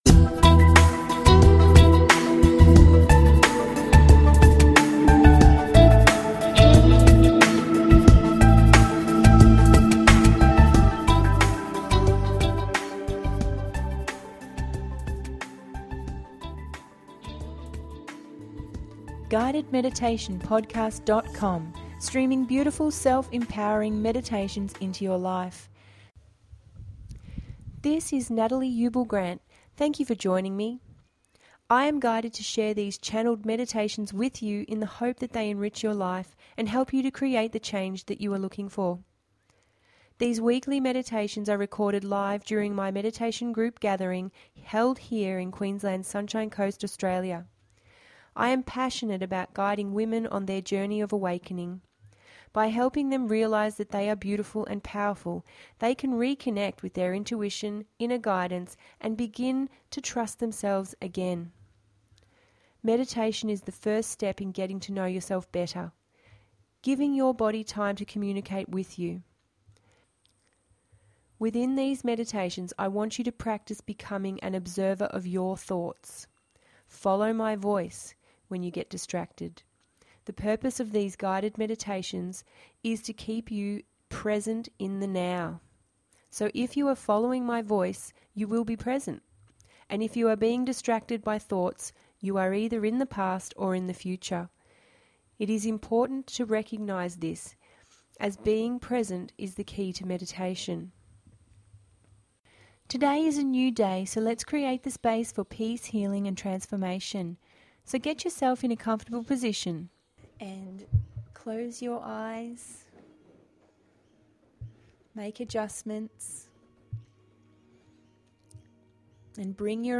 Ocean Release…ep 16 – GUIDED MEDITATION PODCAST